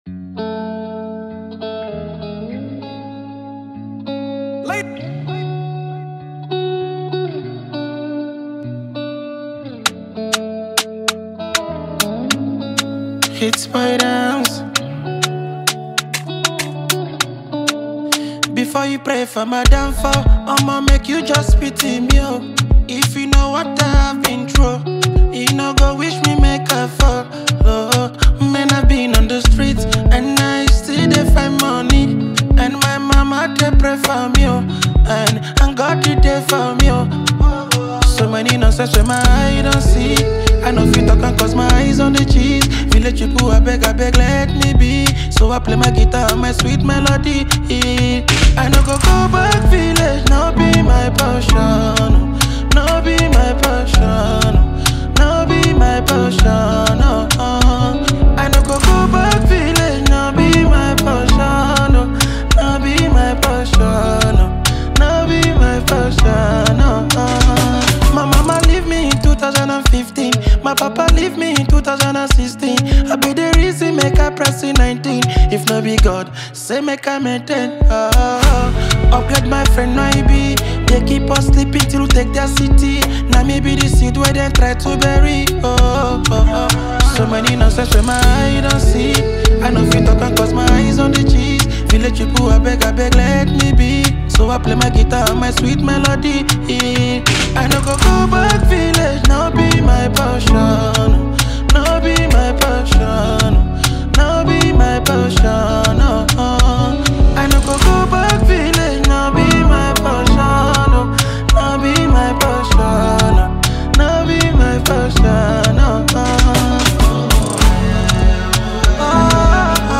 Nigerian singer-songwriter and talented artist